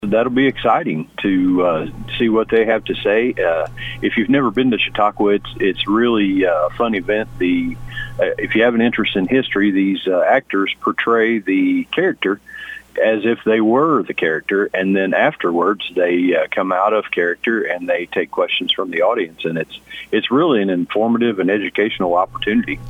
Bonne Terre City Administrator Shawn Kay explains what Big River Chautuaqua is all about.